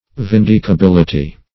Meaning of vindicability. vindicability synonyms, pronunciation, spelling and more from Free Dictionary.